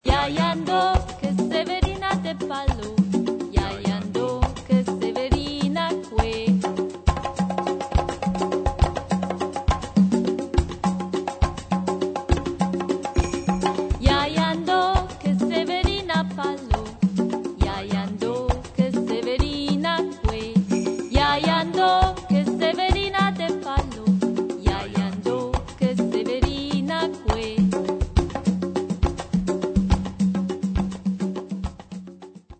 CARRIBEAN